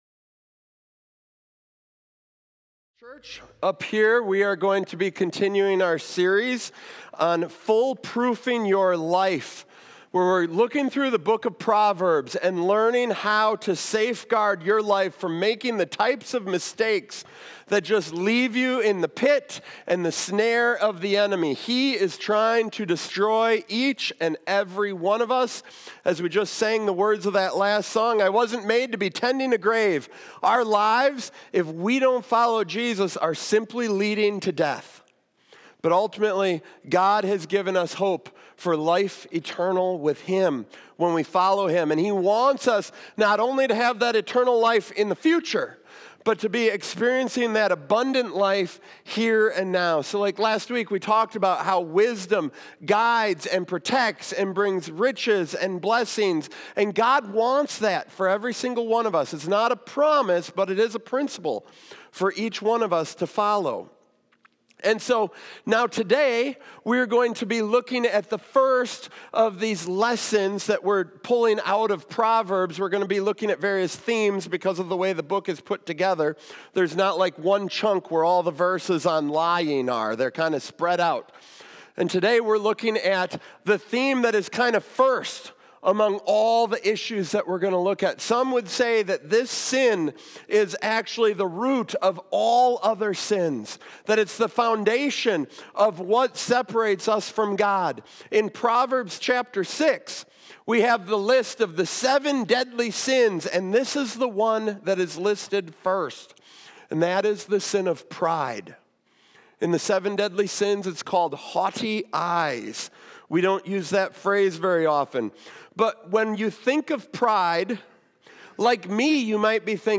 Whether you’re dealing with excessive competitiveness, materialism for status, or the exhausting pressure to always prove yourself, this sermon offers the biblical antidote that will transform your relationships and bring genuine peace to your life.